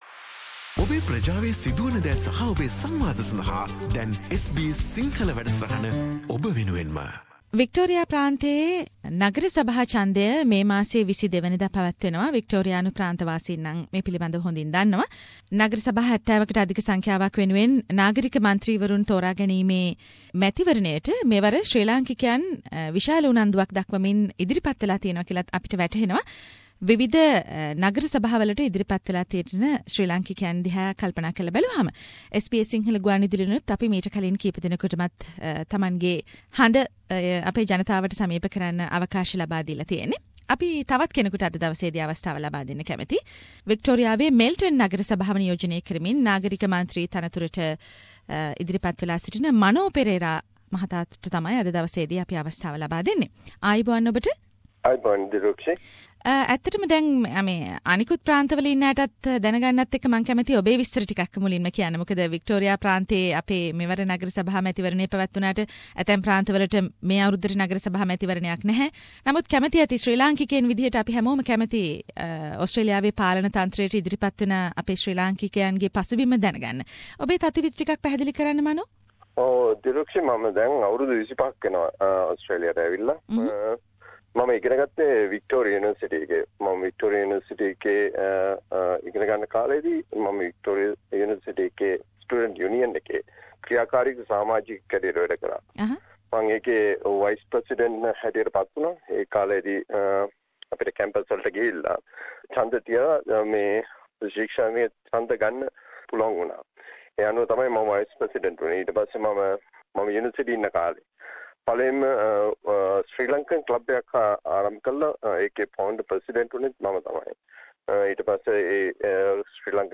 SBS Sinhalese program interviewed another council election candidacy who has Sri Lankan background